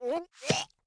Npc Catspit Sound Effect
npc-catspit-3.mp3